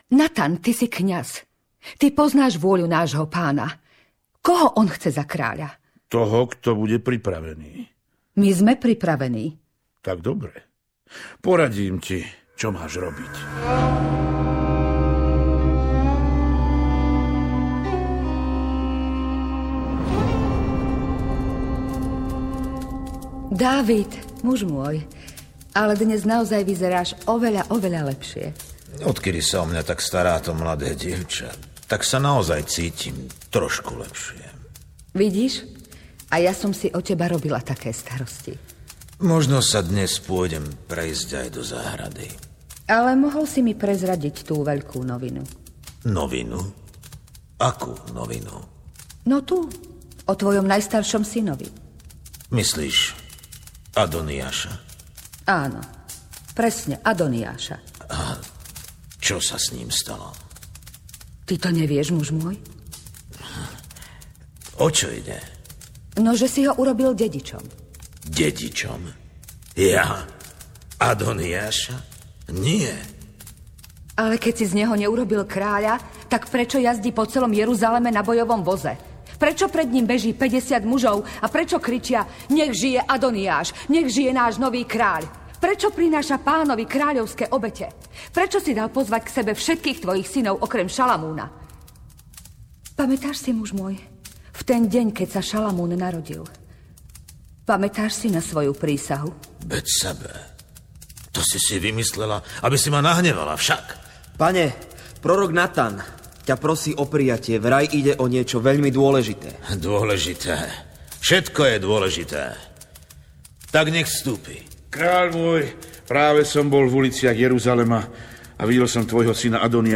Rodinná edícia biblických príbehov formou rozhlasovej dramatizácie približuje záujemcom nový, netradičný pohľad na jednotlivé knihy Starého zákona.
Audio kniha
Ukázka z knihy